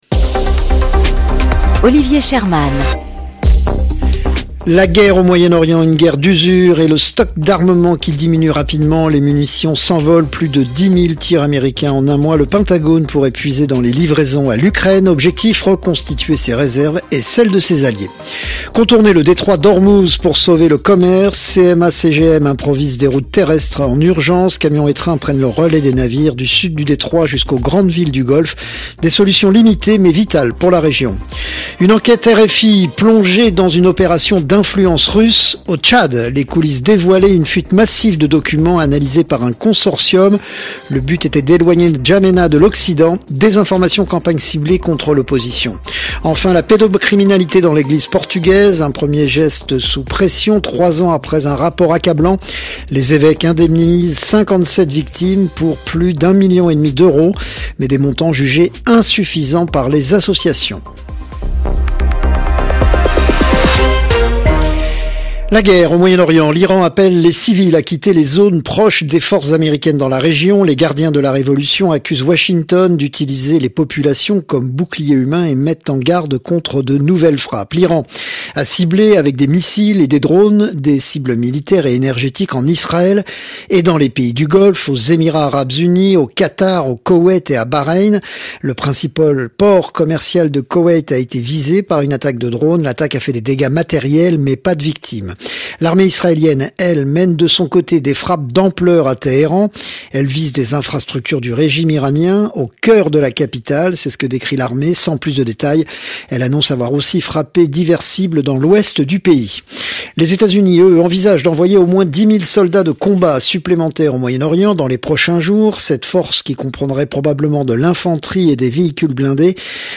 Flash infos 27/03/2026